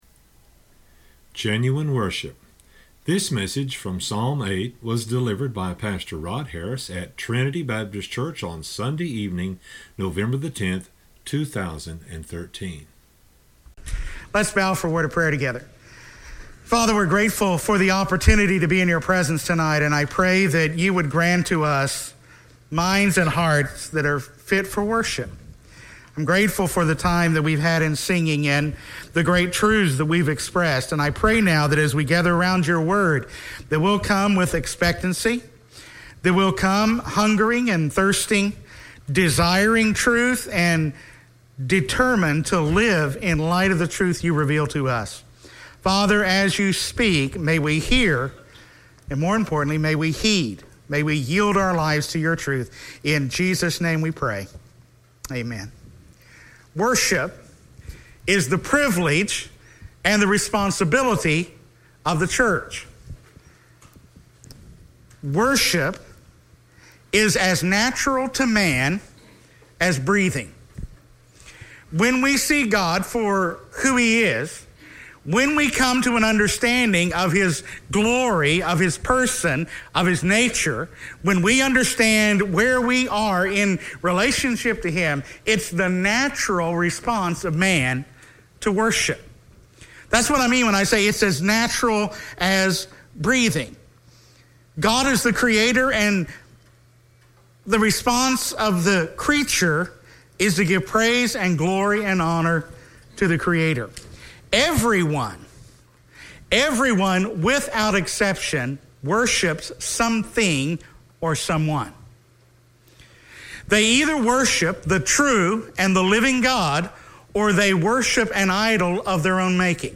at Trinity Baptist Church on Sunday evening, November 10, 2013.